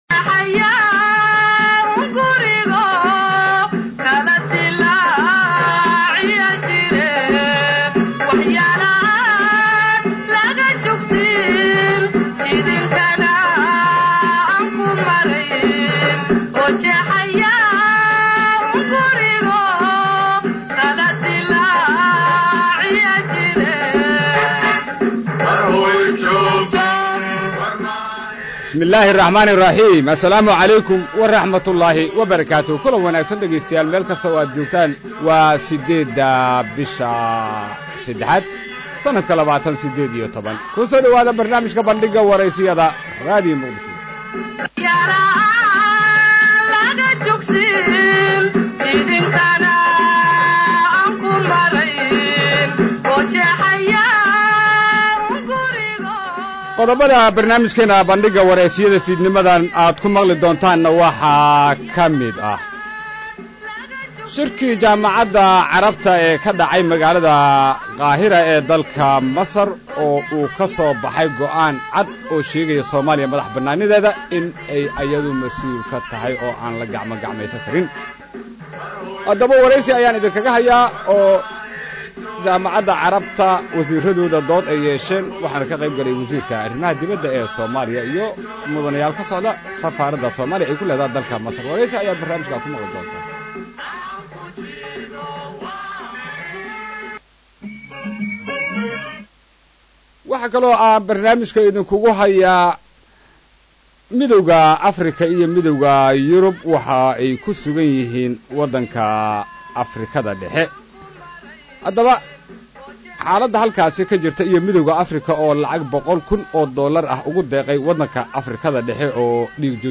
Dhageyso Barnaamijka Bandhiga wareysiyada ee Radio Muqdisho